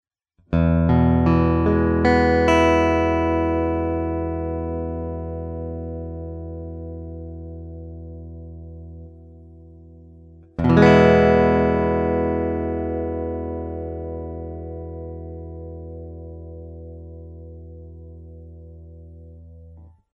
Вот некоторые звуковые файлы (ничего особенного, просто арпеджио ми мажор сопровождаемый мажорным аккордом) с моим Macassar Ebony T5 в различных положениях (от 1 до 5):
Это прямо из гитары в мой 4-х дорожечный рекордер Fostex CompactFlash.